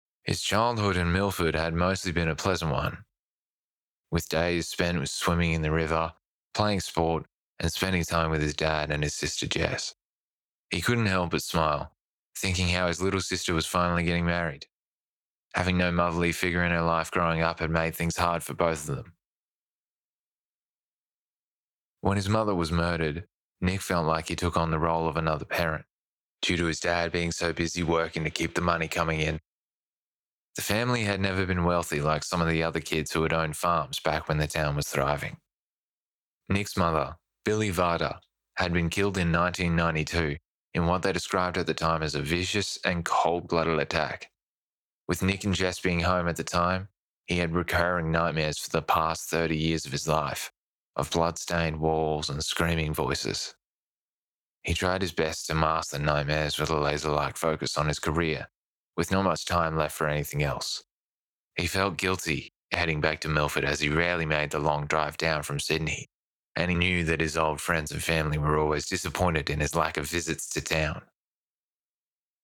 Adult Crime Novel - Waranilla [HARD AUSTRALIAN, CHRACTER]
Young Adult